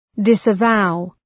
Προφορά
{,dısə’vaʋ}